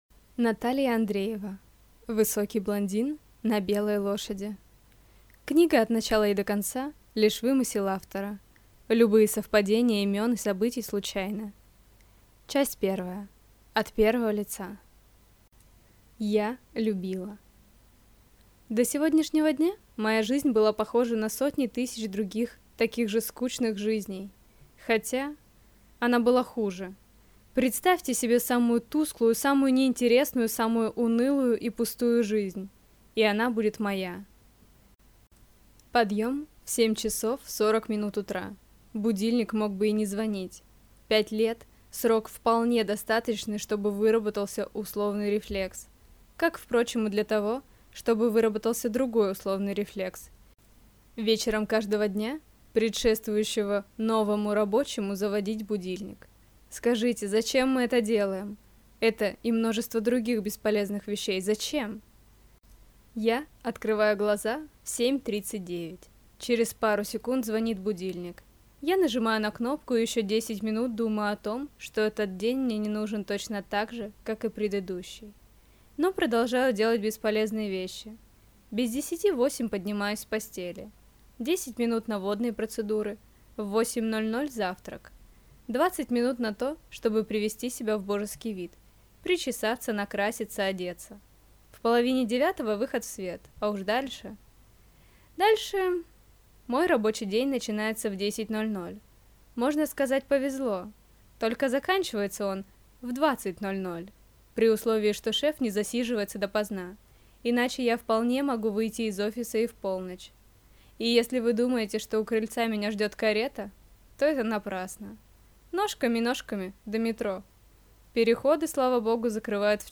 Аудиокнига Высокий блондин на белой лошади | Библиотека аудиокниг